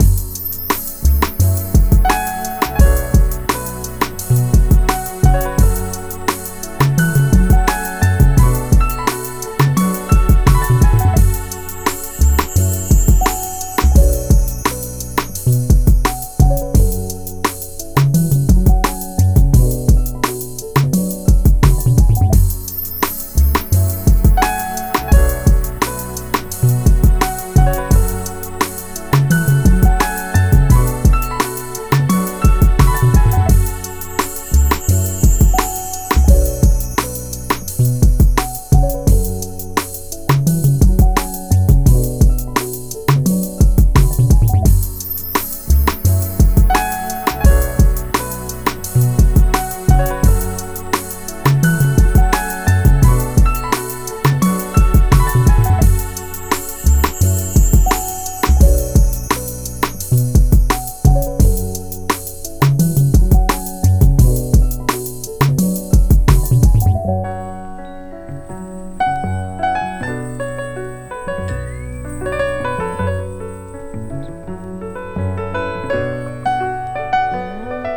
Beat Pack/